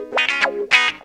GTR 90 G#M.wav